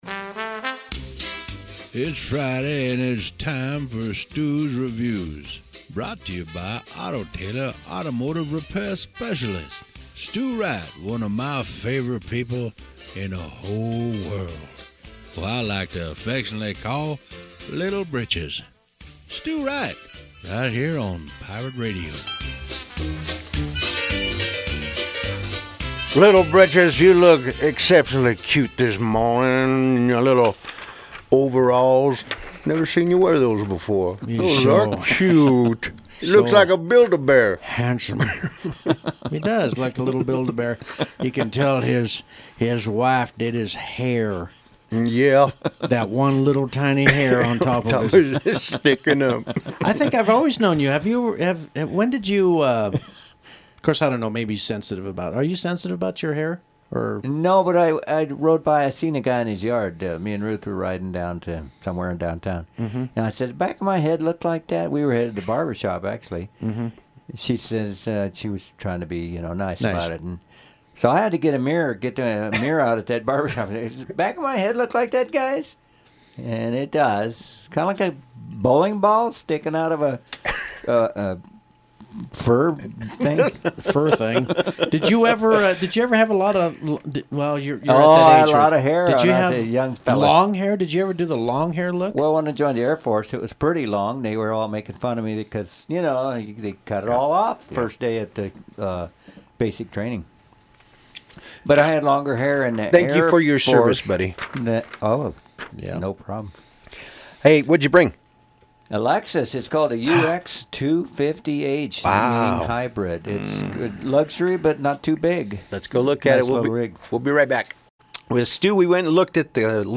The Lexus was driven to Pirate Radio for a review